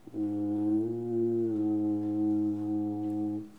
Les sons ont été découpés en morceaux exploitables. 2017-04-10 17:58:57 +02:00 620 KiB Raw History
bruit-animal_14.wav